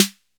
Index of /90_sSampleCDs/Roland L-CDX-01/DRM_Drum Machine/SNR_Cheese Snrs
DRM 808 SN13.wav